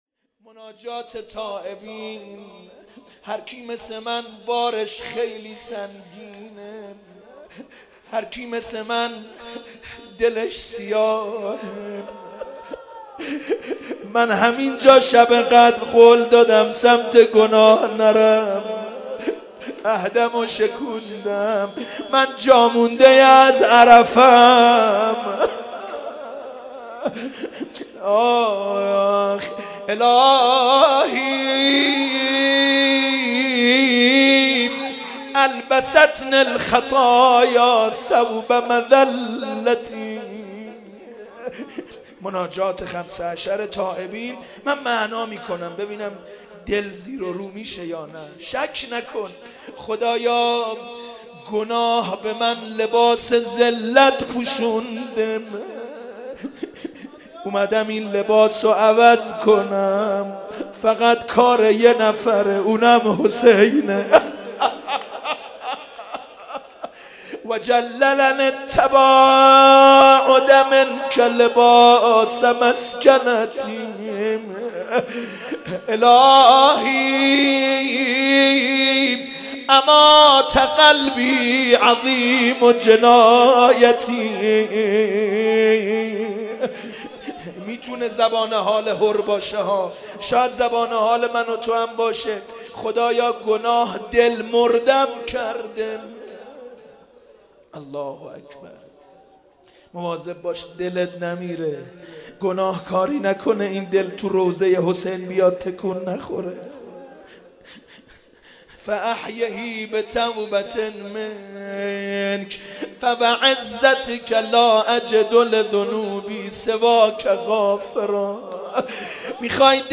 شب چهارم محرم94_مناجات_ الهی البستنه الخطایا